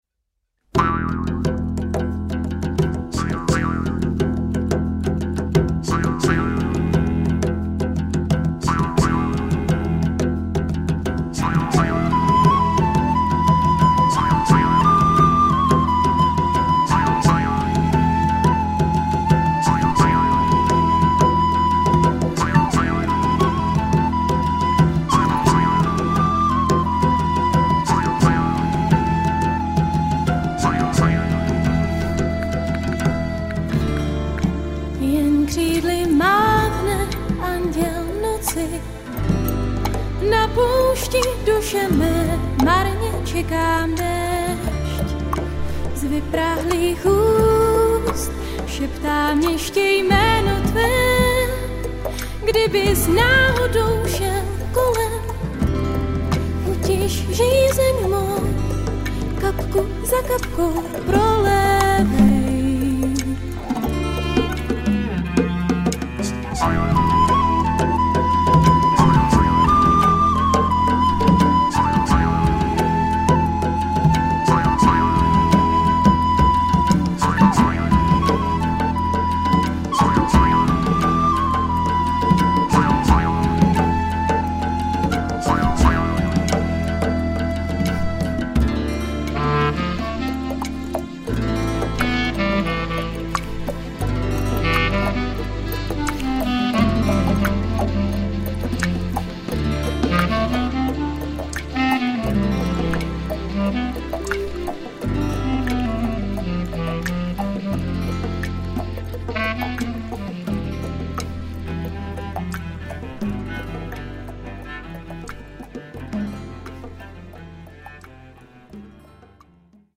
Žánr: Folk.